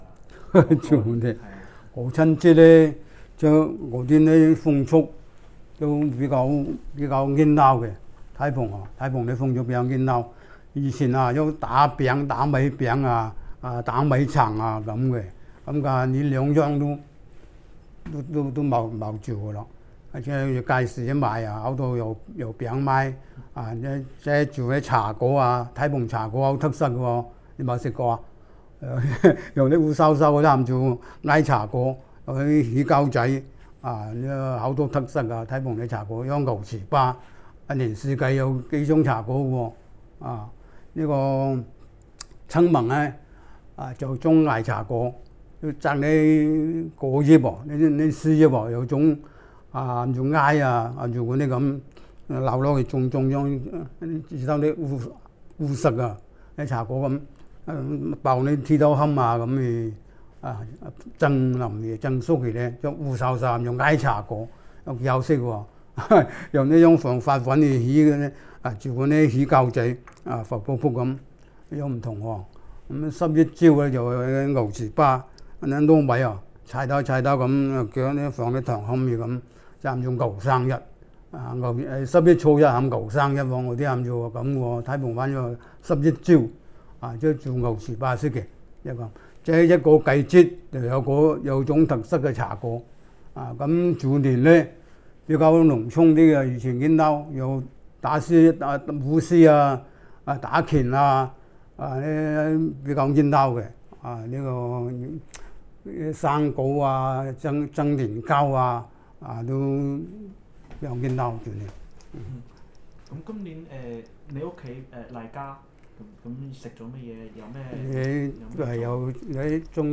Male, 72
digital wav file recorded at 44.1 kHz/16 bit on Zoom H2 solid state recorder
Dapeng, Shenzhen, Guangdong Province, China
Dapeng dialect in Shenzhen, China